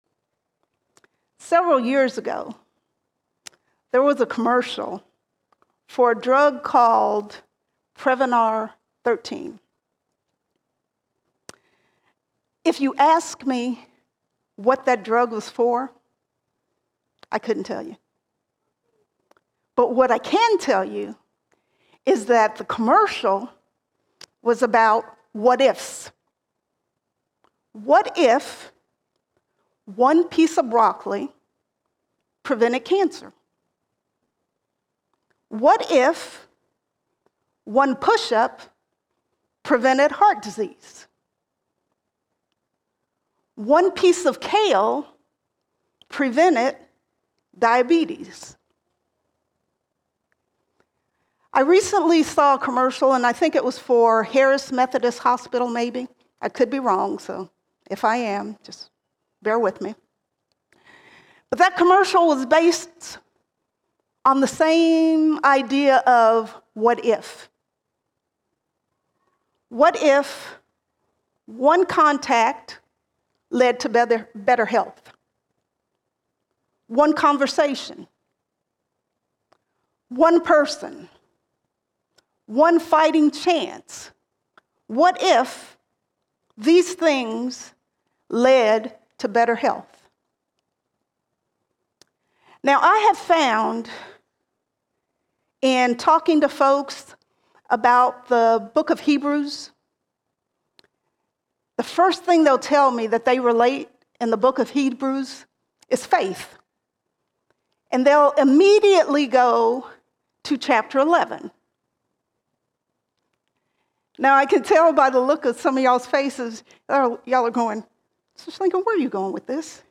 22 April 2024 Series: Sunday Sermons Topic: Jesus All Sermons Singular Necessity Singular Necessity Christ and His work is the singular need we all have.